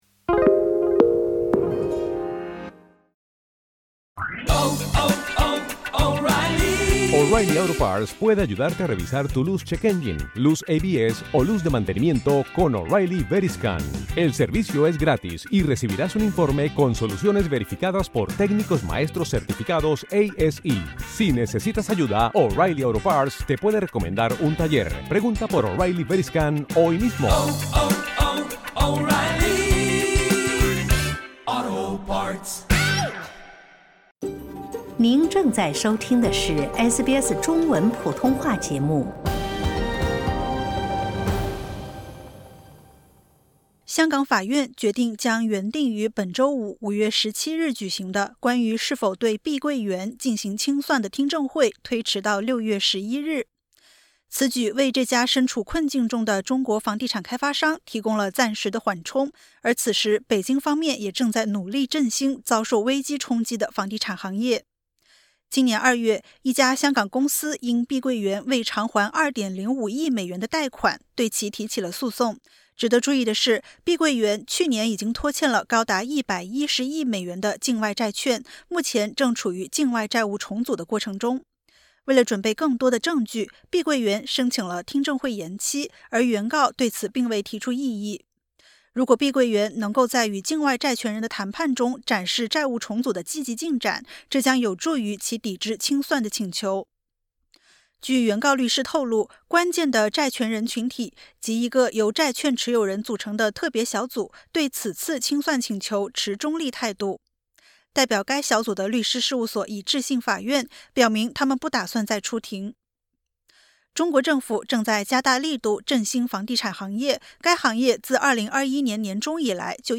碧桂园因债务问题面临清算，目前法院将听证会推迟至6月11日。点击 ▶ 收听完整报道。